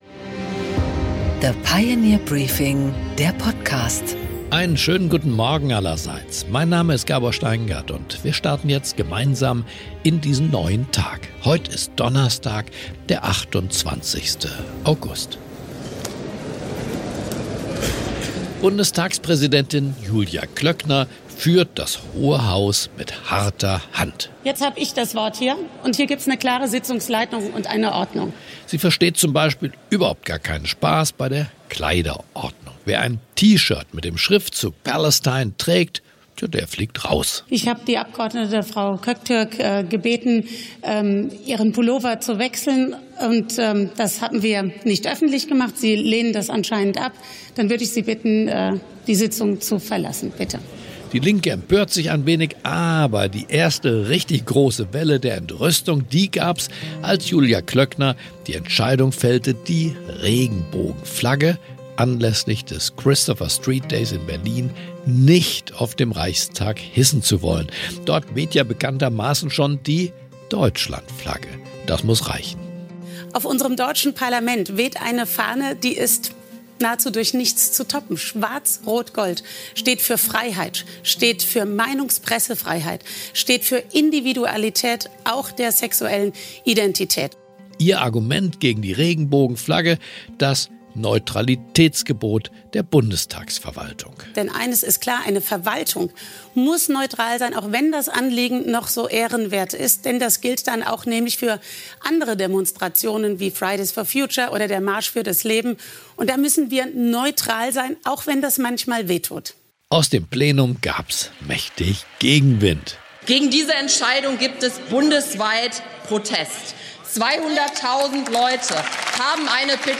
Gabor Steingart präsentiert das Pioneer Briefing.